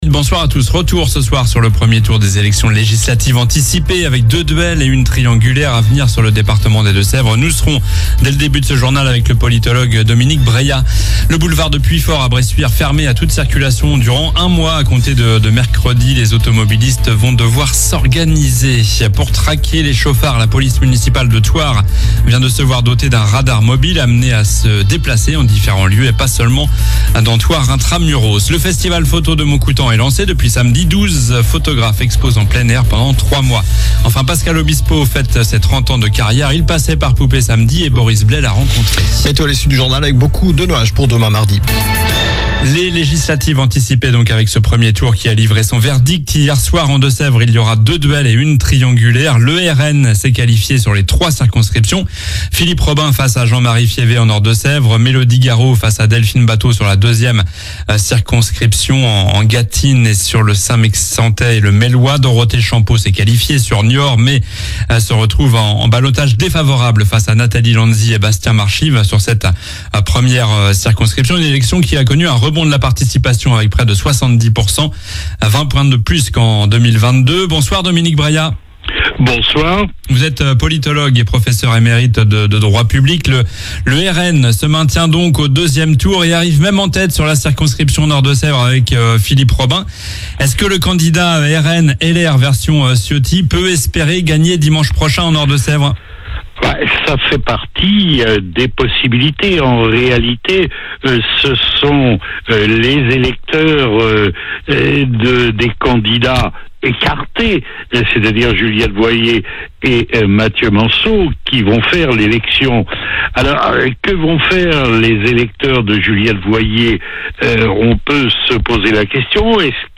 Journal du lundi 1er juillet (soir)